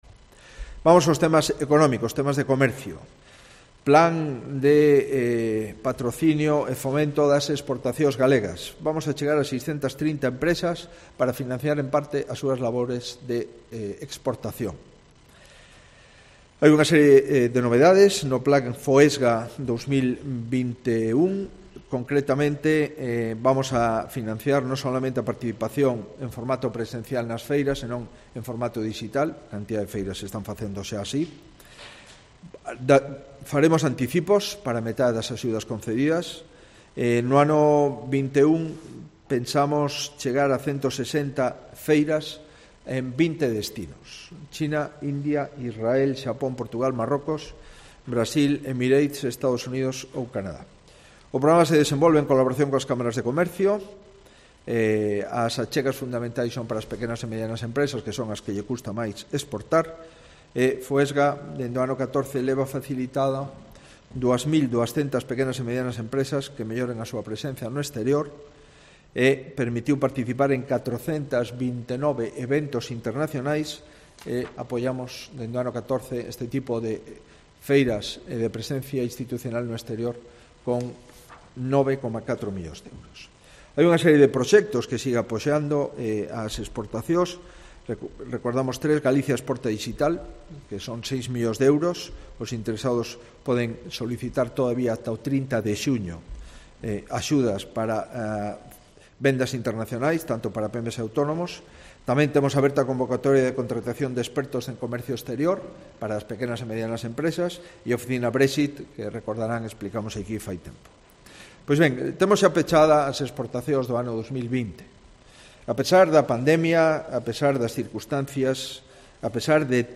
Alberto Núñez Feijóo explica la evolución de las exportaciones en Galicia en 2020